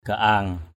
/ɡ͡ɣa-a:ŋ/ (t.) nồng, gắt = âcre et fort. plak gaang tarakaong p*K ga/ tr_k” chát đến gắt họng.